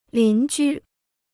邻居 (lín jū) Dictionnaire chinois gratuit